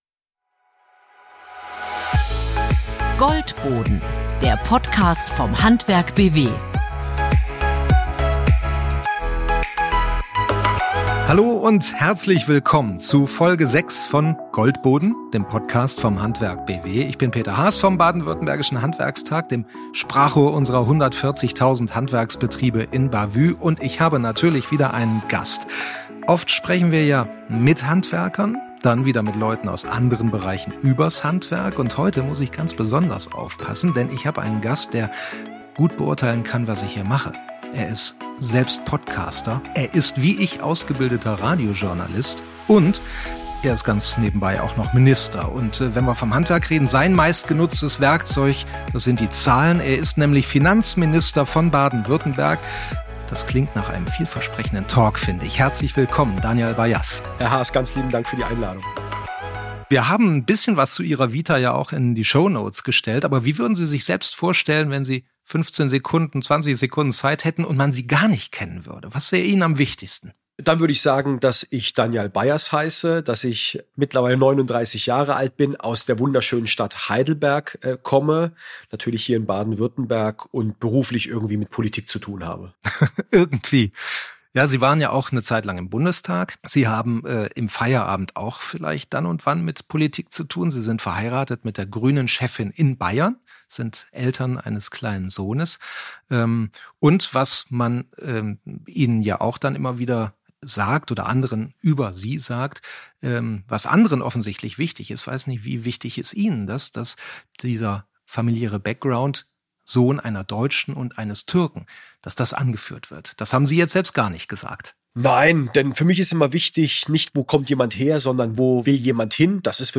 mit dem Finanzminister von Baden-Württemberg: Danyal Bayaz.